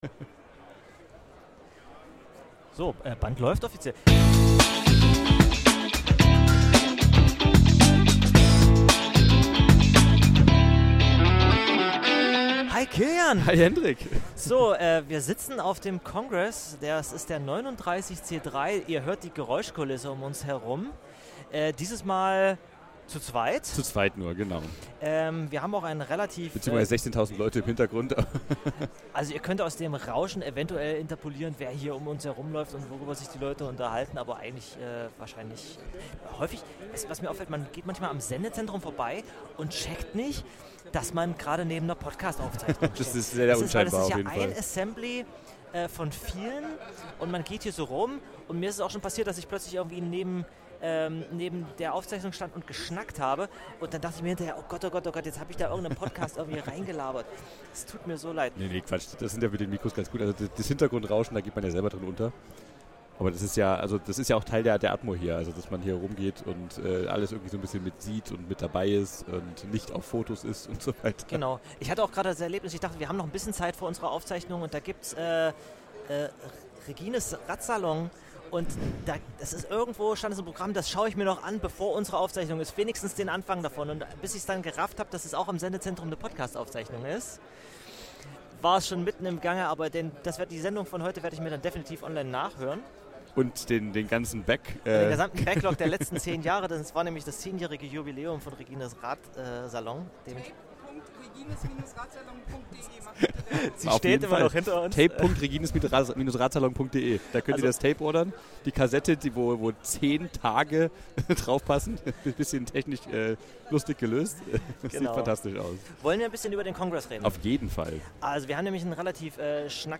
Live und in Farbe